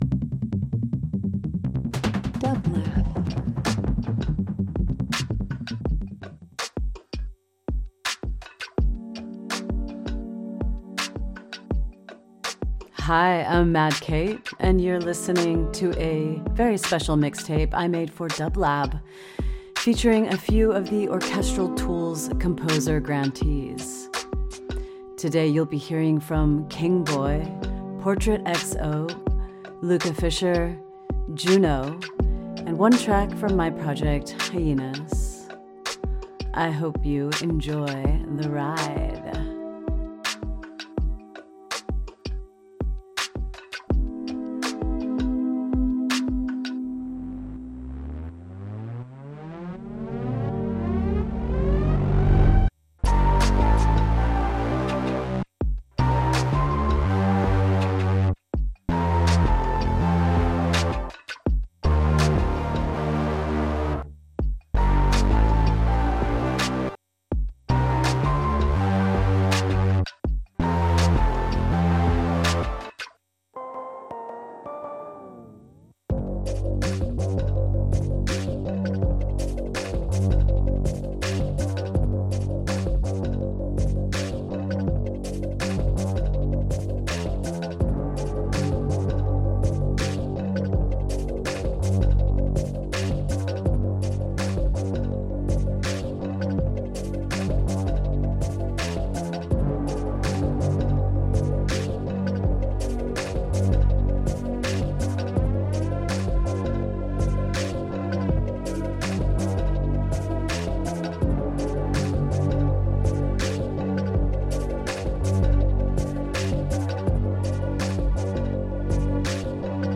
a short hour of ambient and electronic compositions
Ambient Electronic Minimal